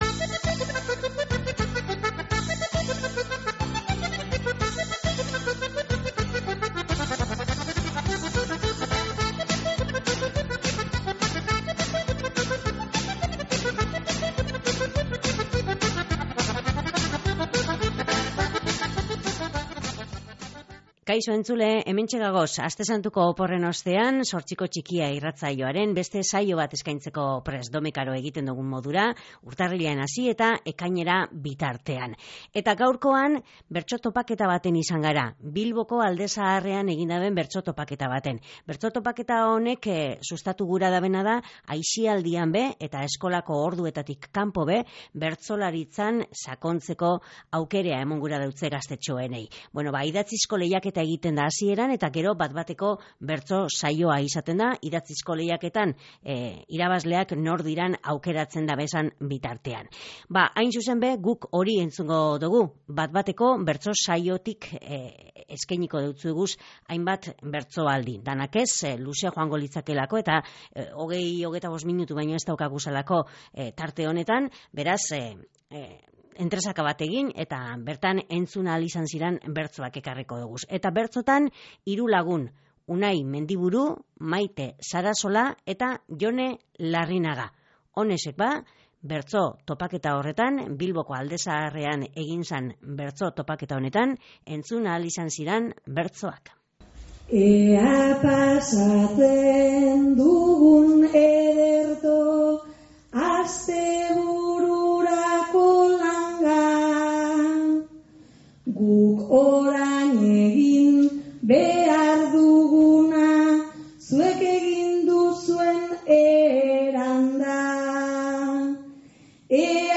Idatzizko bertso lehiaketaren ostean, bat-bateko saioa entzuteko aukerea izan eben partehartzaileek
Oinak buruan bertso topaketa Bilboko Zazpikaleetan